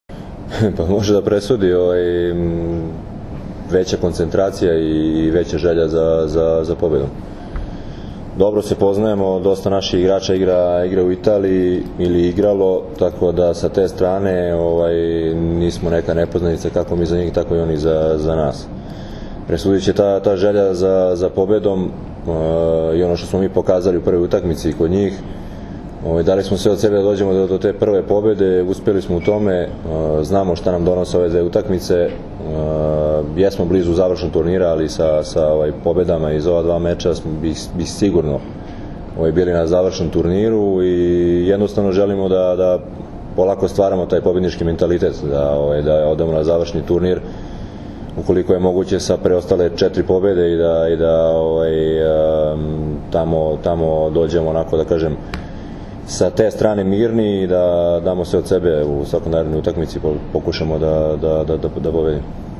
U novosadskom hotelu “Sole mio” danas je održana konferencija za novinare, kojoj su prisustvovali Dragan Stanković, Nikola Grbić, Dragan Travica i Mauro Beruto, kapiteni i treneri Srbije i Italije.
IZJAVA DRAGANA STANKOVIĆA